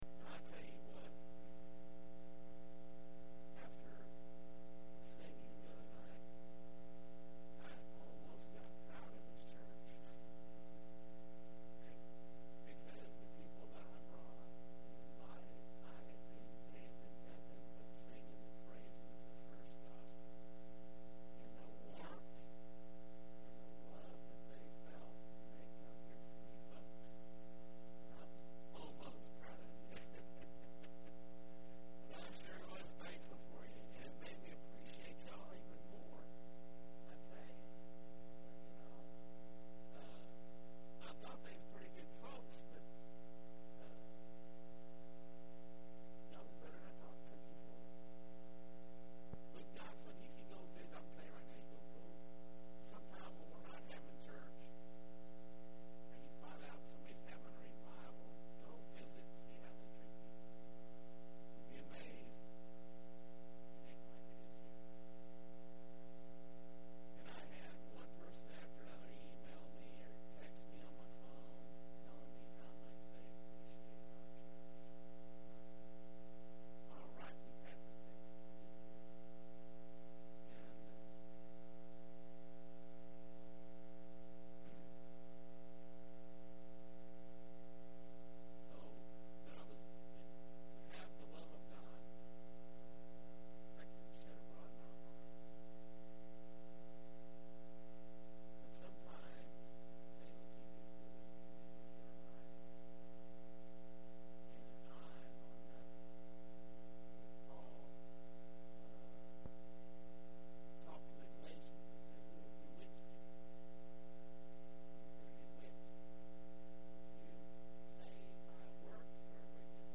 06/09/10 Wednesday Service